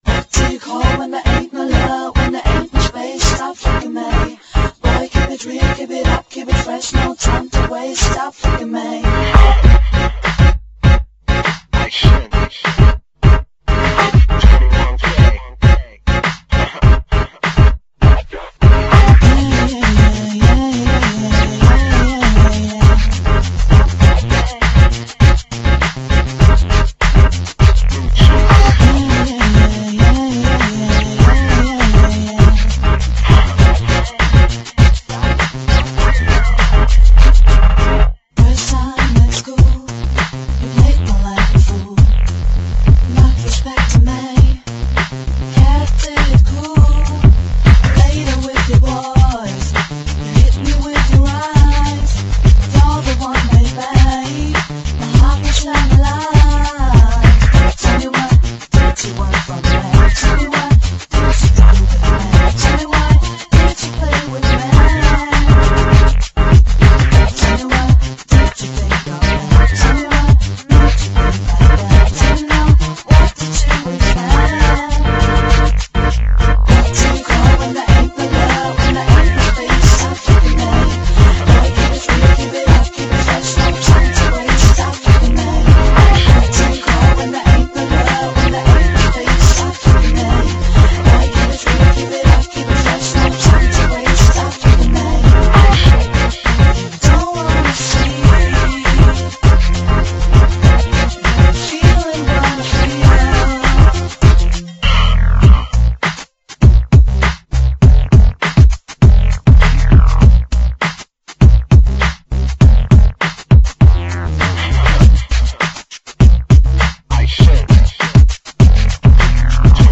vocals
demo-trax
r&b, hip hop, classical soul